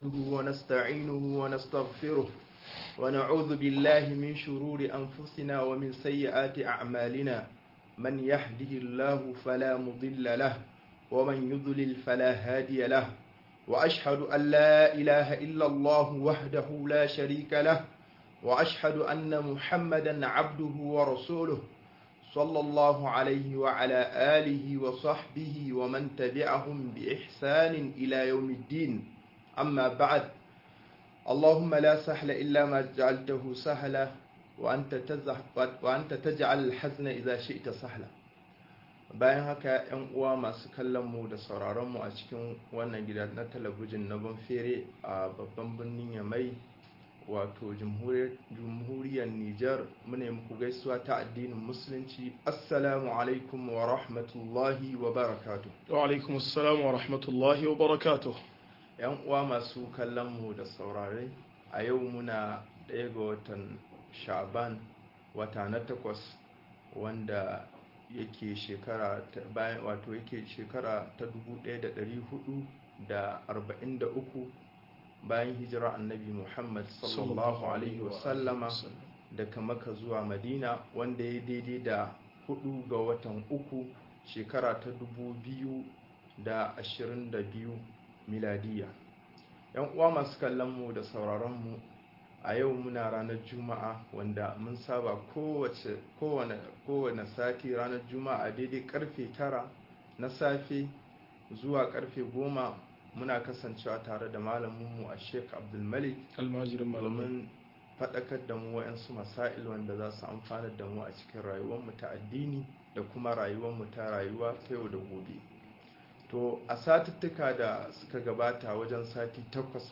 Sunaye da haƙiƙanin ma'anar su - MUHADARA